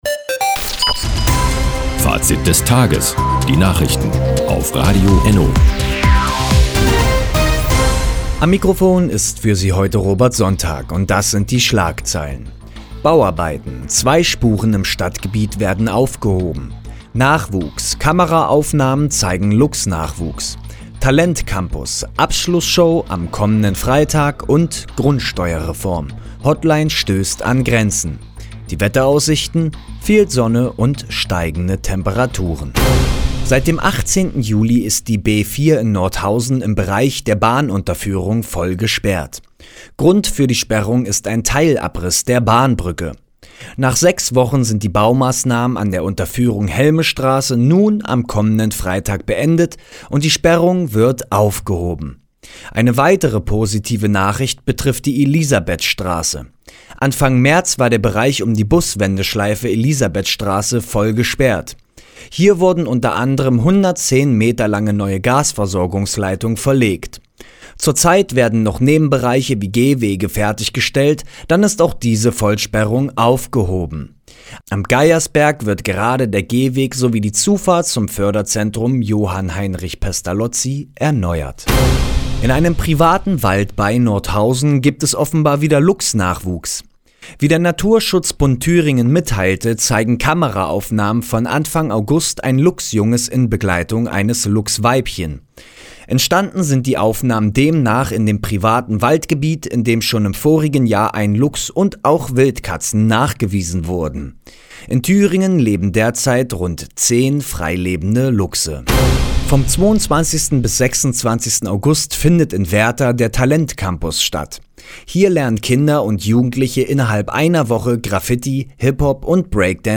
Die tägliche Nachrichtensendung ist jetzt hier zu hören...